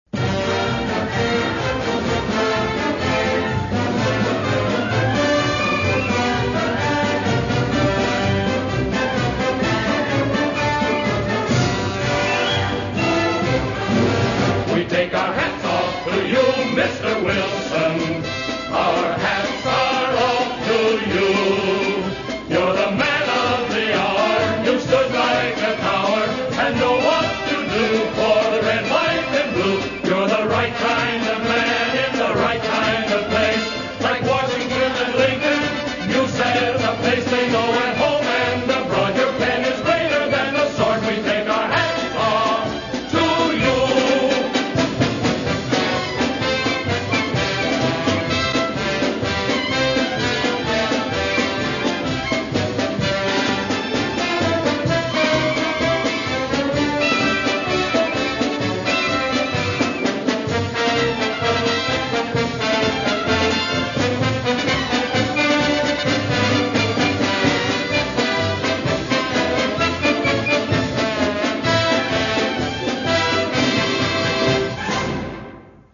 is a rousing cornucopia